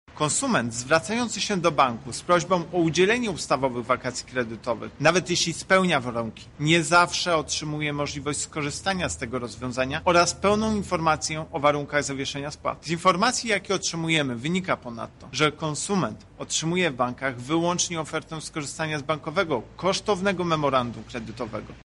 • wyjaśnia Prezes UOKiK Tomasz Chróstny.